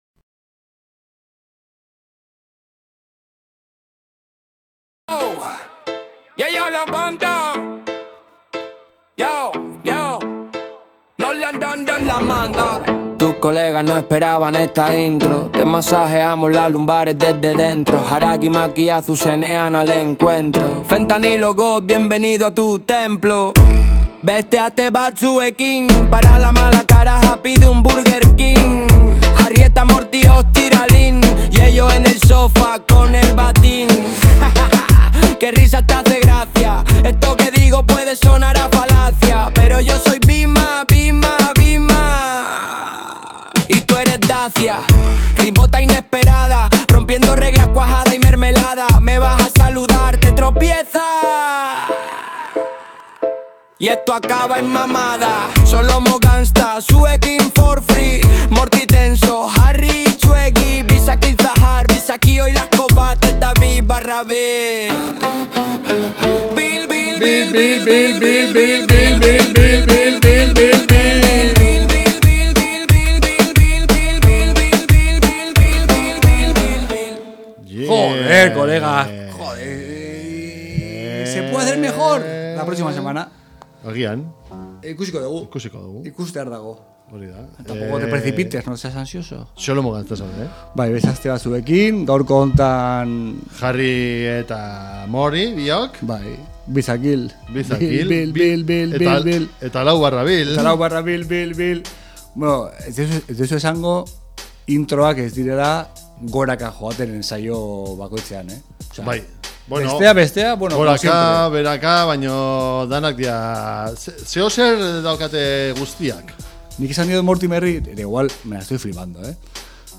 Mundu osoko Rapa, entrebistak zuzenean, Bass doinuak eta txorrada izugarriak izango dituzue entzungai saio honetan.